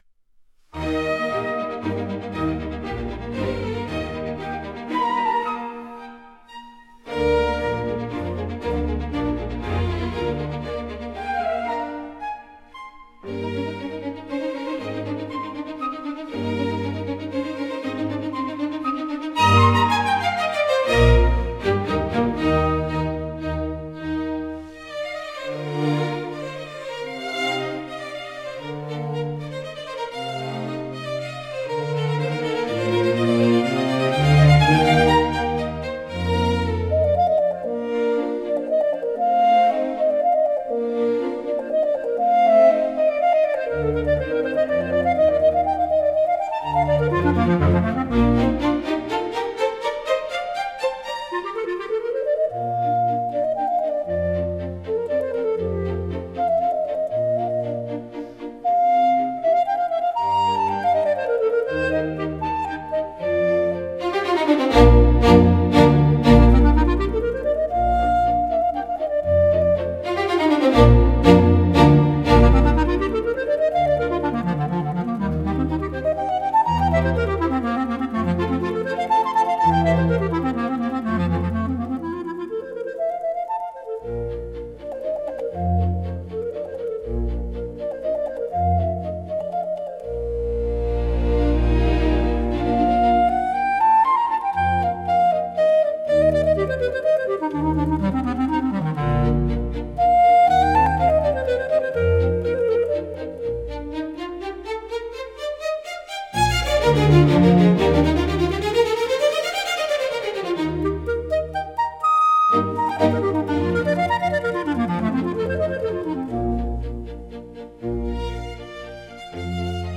聴く人に高雅さや荘厳さ、心の深みを感じさせる普遍的なジャンルです。